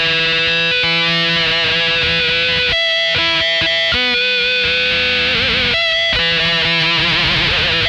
Index of /90_sSampleCDs/Zero G - Funk Guitar/Partition I/VOLUME 001